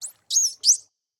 25w18a / assets / minecraft / sounds / mob / dolphin / play2.ogg